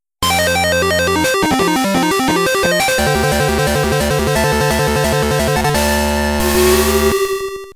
magnettrain.wav